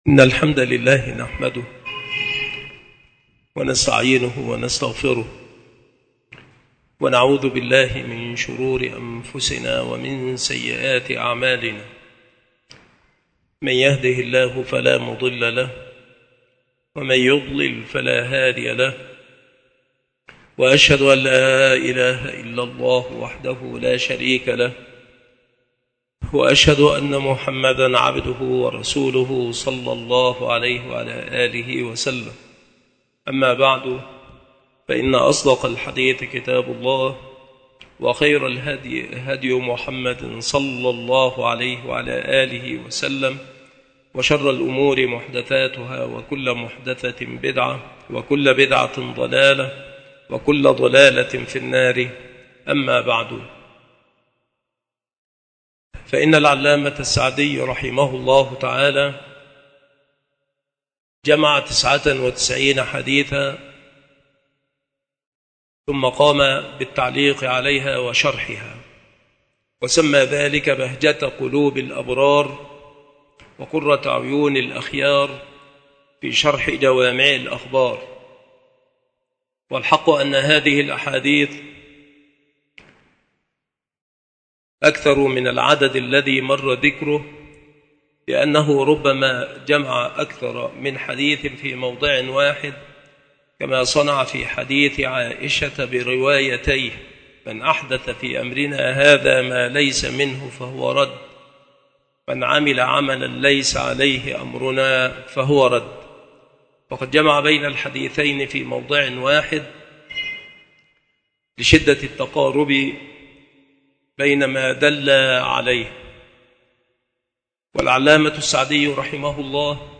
التصنيف شروح الحديث
مكان إلقاء هذه المحاضرة بالمسجد الشرقي بسبك الأحد - أشمون - محافظة المنوفية - مصر